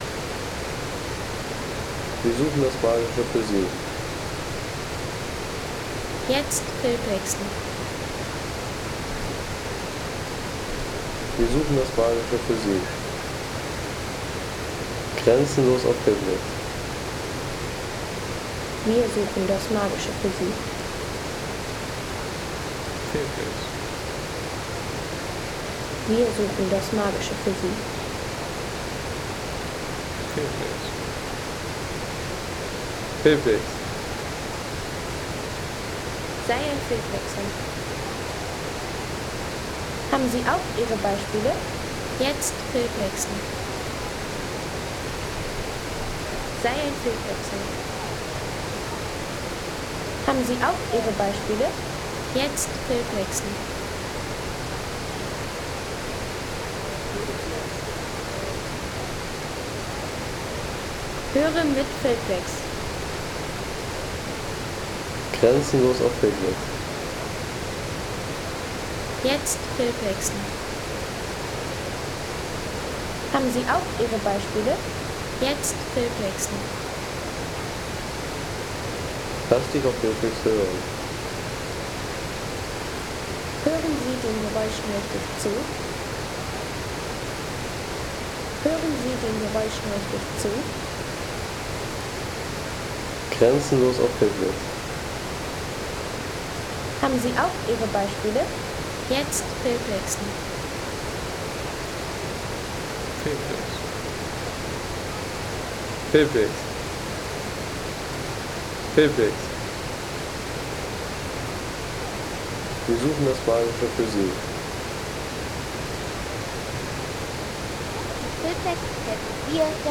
Klang der Kaskaden im Nationalpark Plitvicer Seen
Landschaft - Wasserfälle